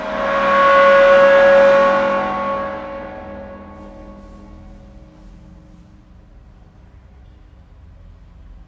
描述：在Baschet Sound Sculpture中演奏弓，木和金属的声音